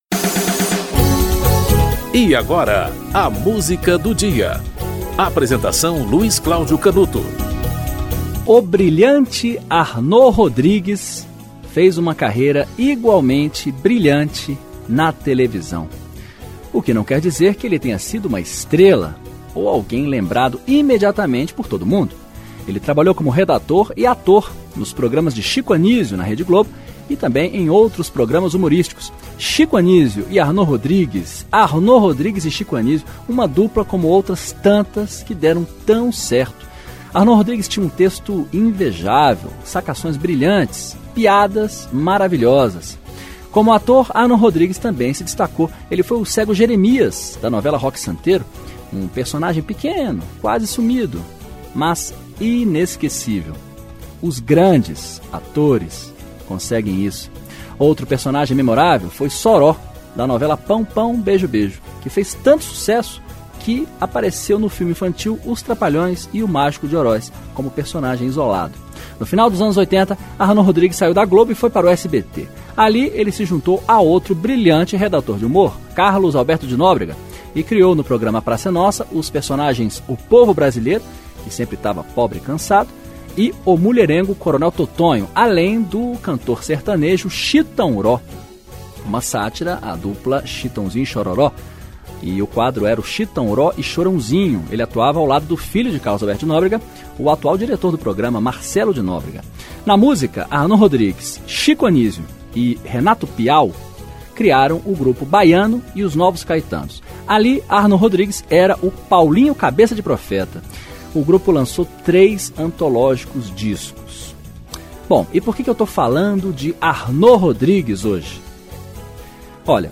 Produção e apresentação: